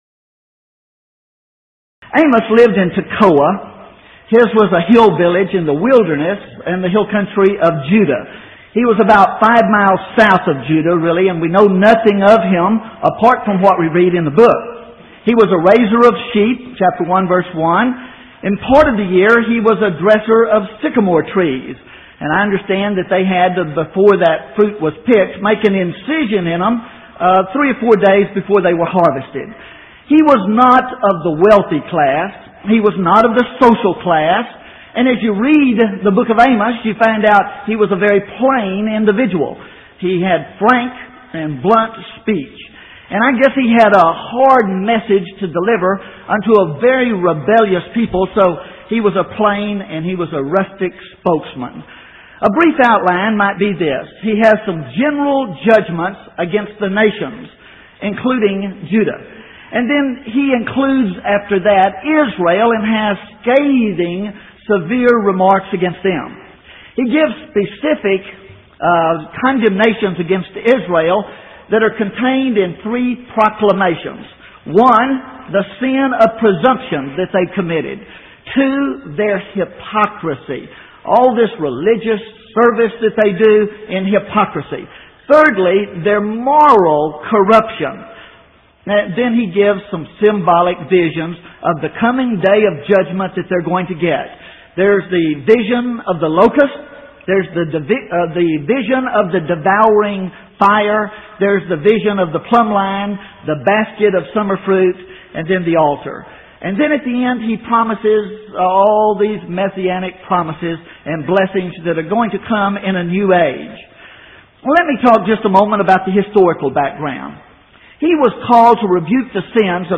Event: 1990 Power Lectures
lecture